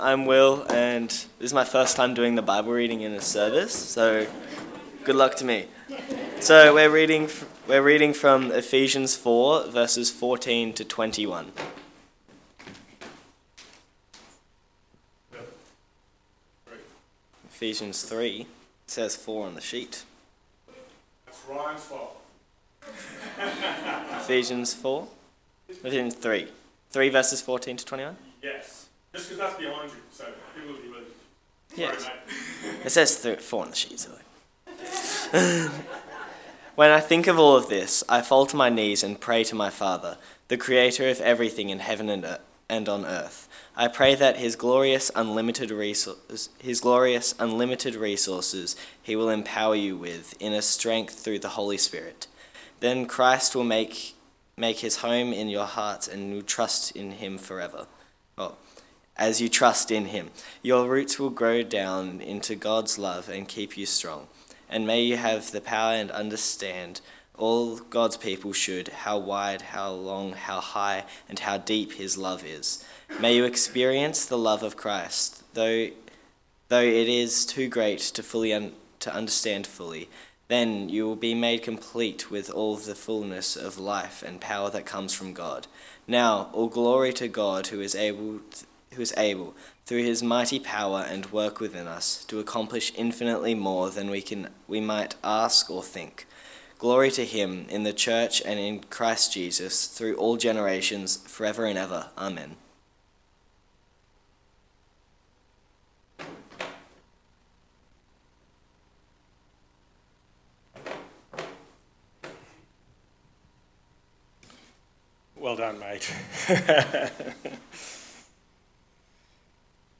Text: Ephesians 3: 14-21 Sermon